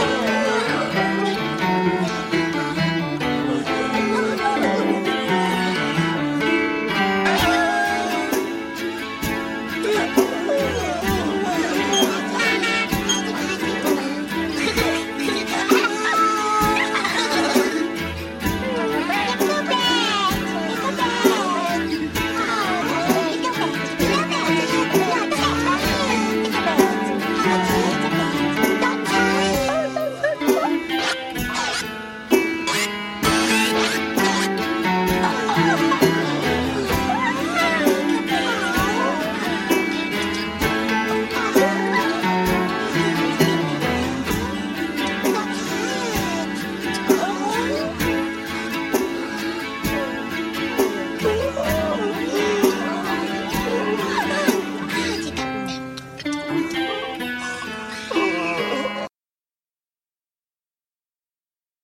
Delightful mini-album of hook-laden songs.